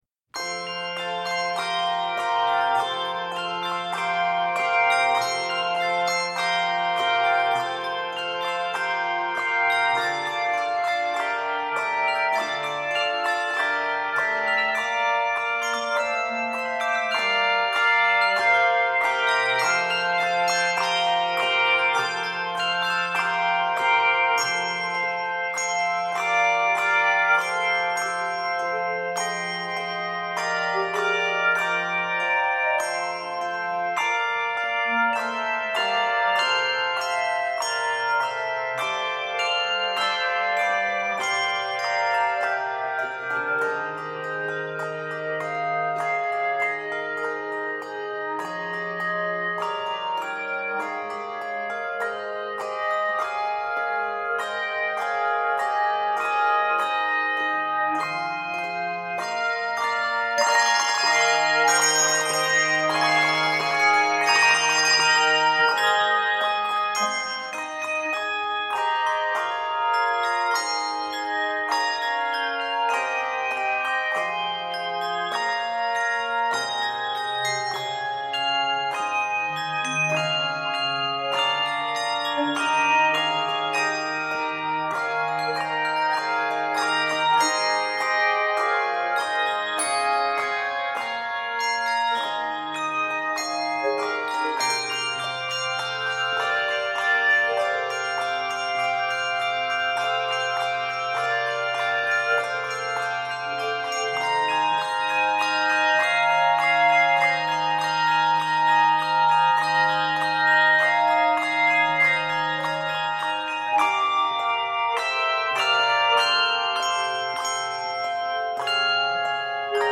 triumphant and regal